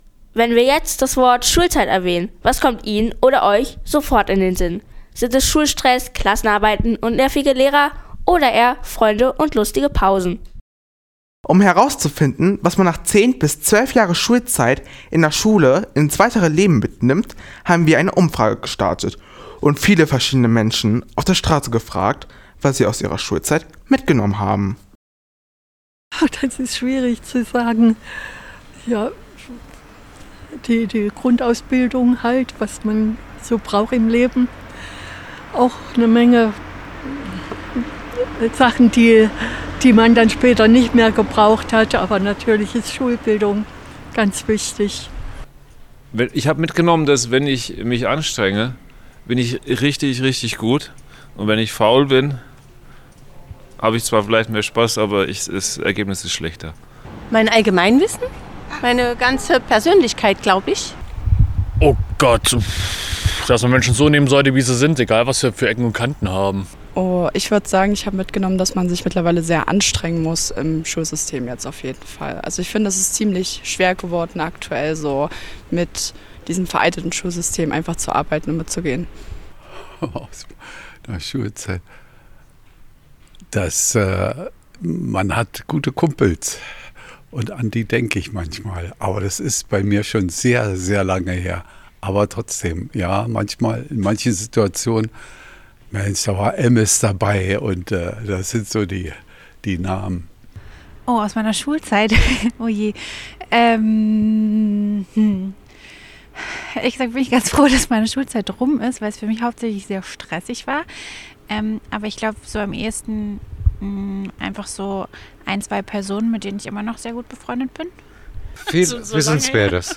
umfrage.schulzeit.antworten.anmoderation.mp3